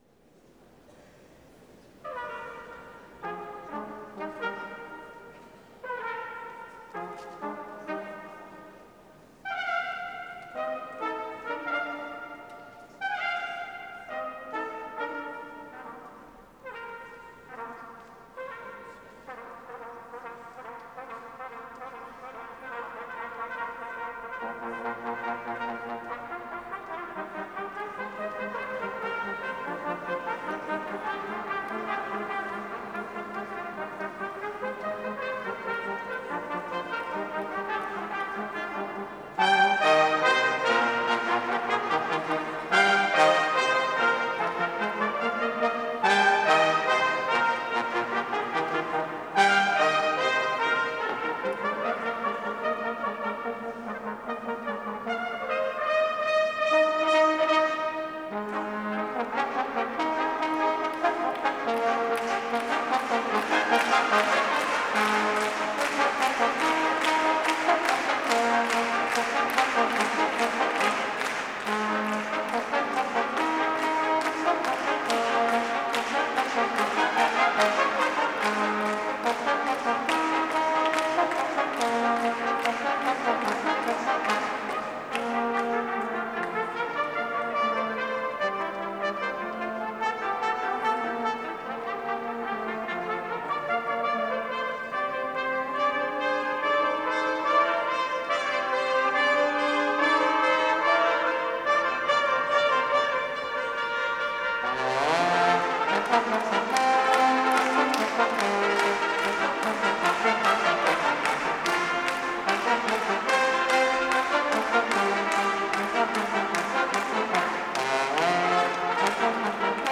FSU Brass Ensemble “Live in Europe” Mar 11-18
Brass Ensemble